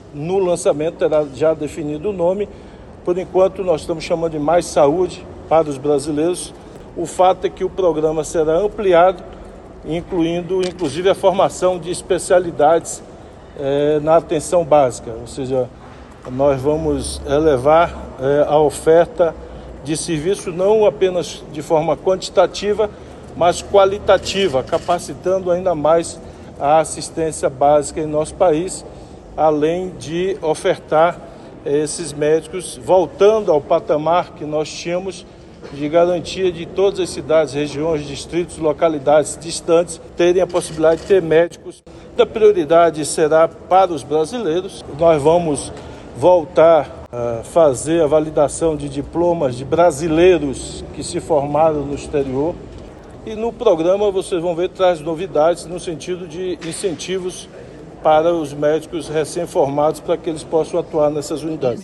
Mais Saúde para os Brasileiros: ministro Rui Costa fala sobre programa que vai levar médicos brasileiros formados no exterior para trabalhar em cidades pequenas - 14/03/23 — Casa Civil